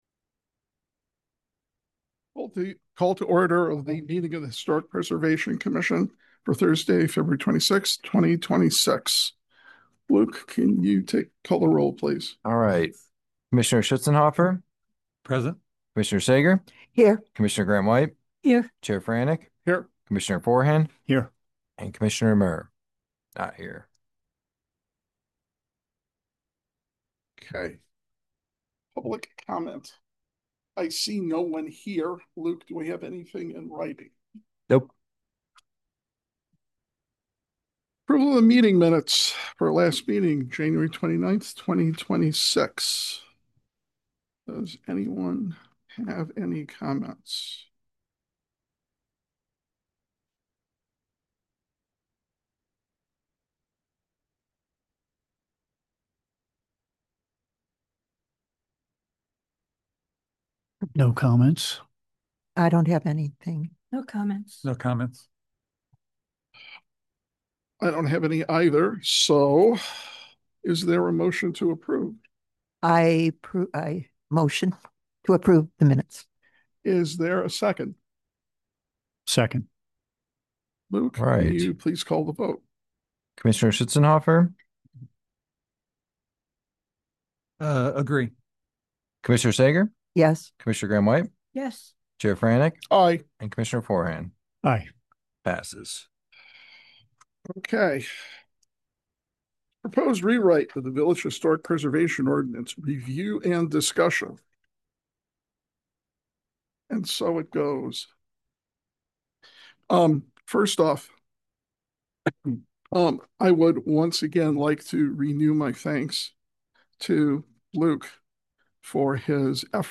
Historic Preservation Commission Meeting